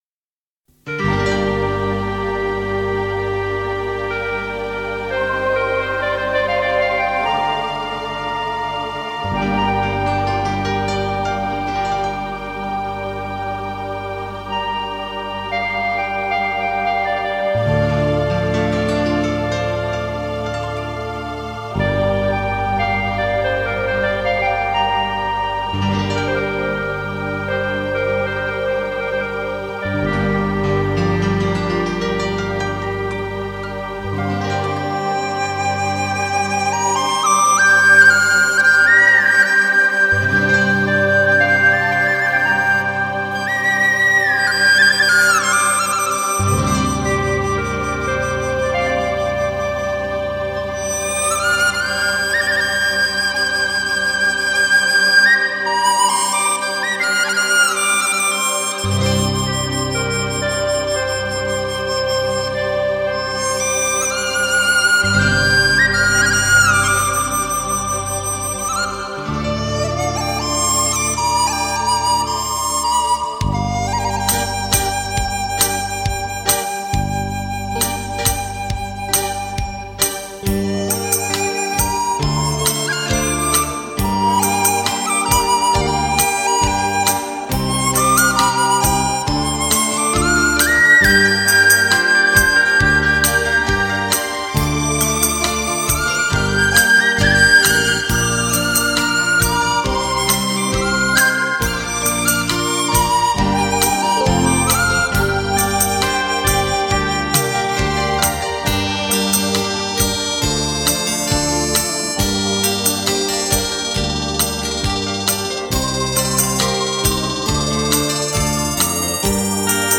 HI-FI 原装德国24K金碟
最具民族色彩的演奏乐曲 最好听的音乐